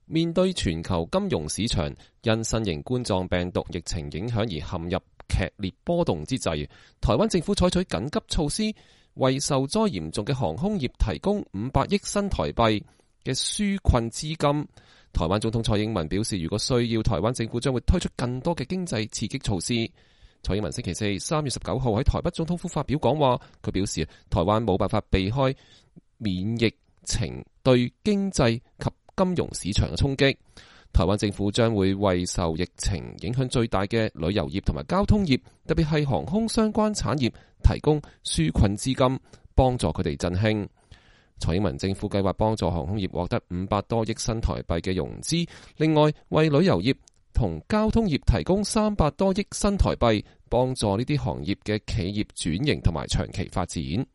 蔡英文週四（3月19日）在台北總統府發表講話。